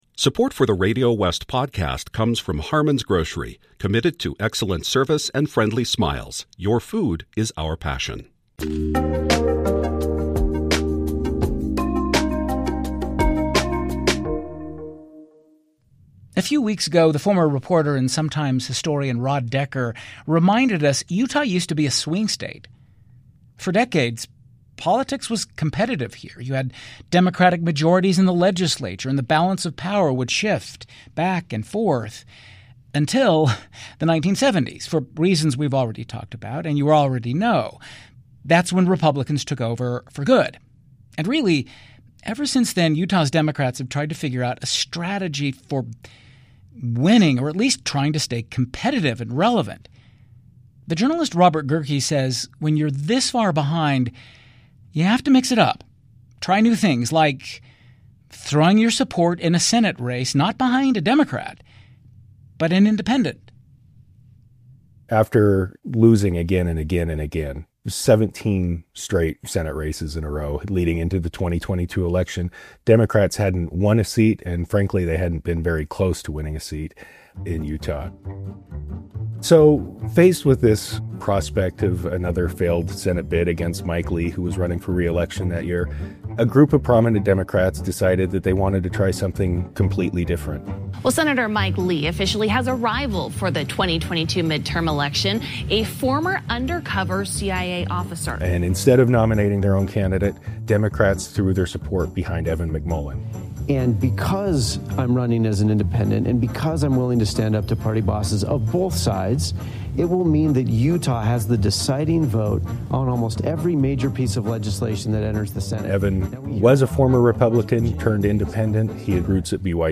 KUER’s award-winning interview show